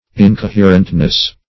Incoherentness \In`co*her"ent*ness\, n.